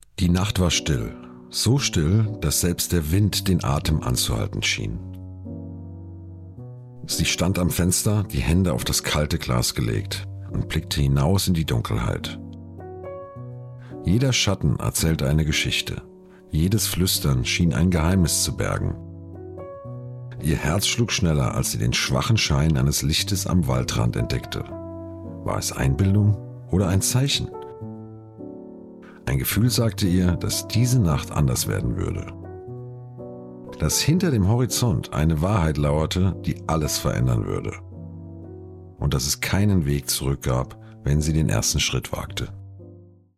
Male
Adult (30-50), Older Sound (50+)
My voice ranges from warm and deep to calm and engaging, making it appropriate for a variety of projects.
Audiobooks
German Audiobook 2
1113ho__rbuch_deutsch.mp3